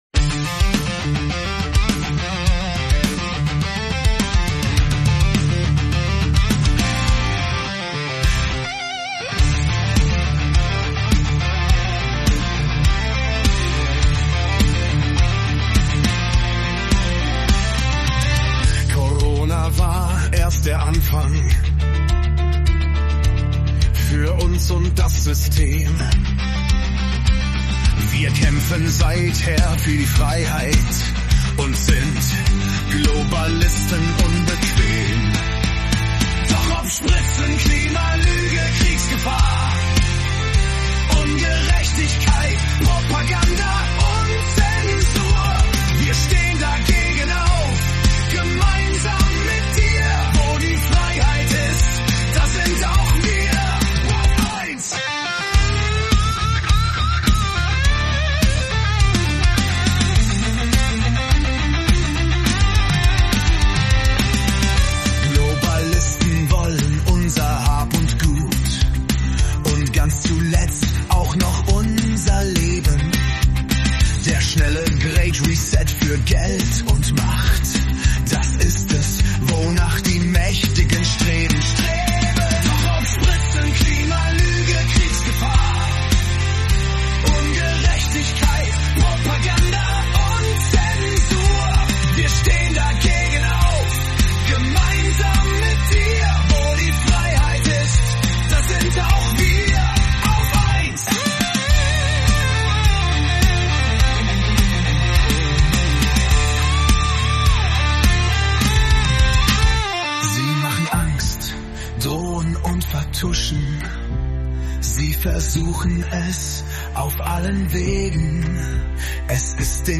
Das ist unser rockiges